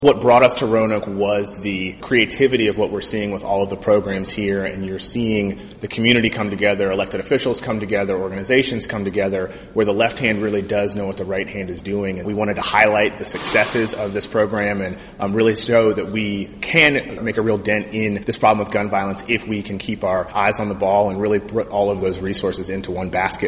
The Democratic candidate for Virginia Attorney General – former Delegate Jay Jones – was in Roanoke this morning, for a roundtable discussion with local law enforcement and public safety officials on efforts to reduce gun violence.